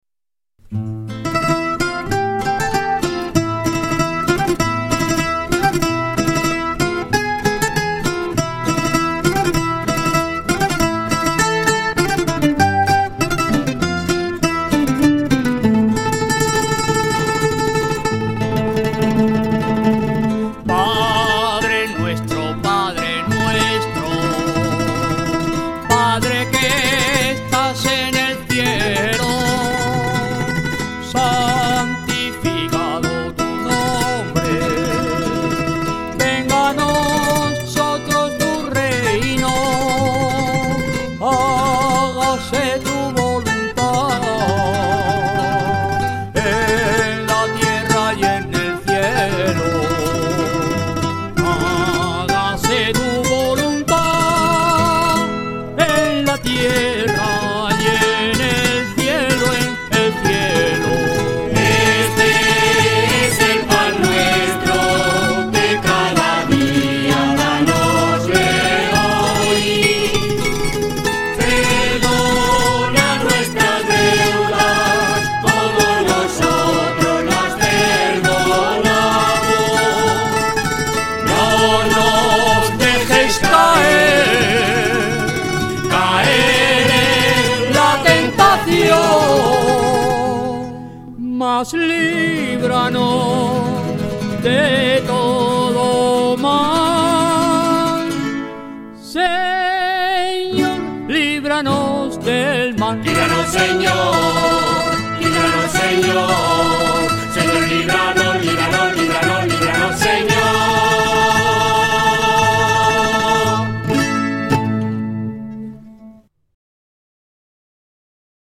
Rondalla Santiago Apostol
06_Padre_nuestro_huertano.mp3